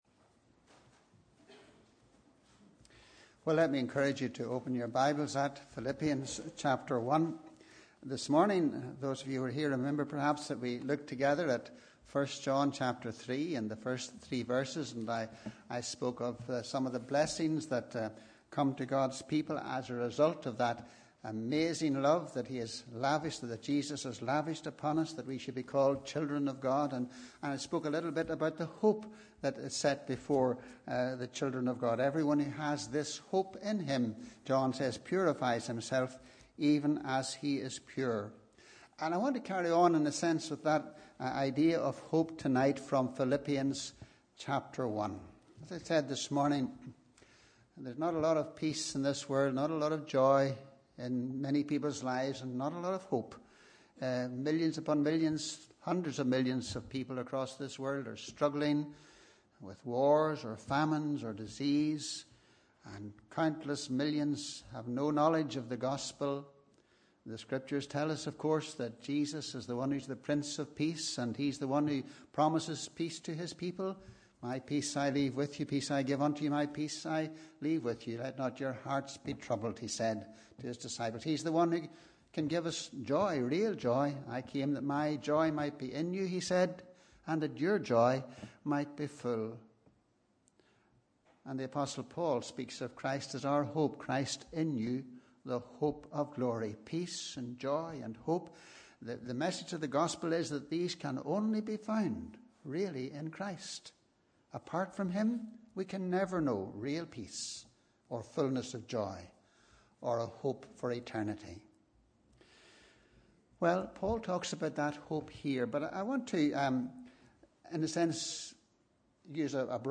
Sunday 13th August 2017 – Morning Service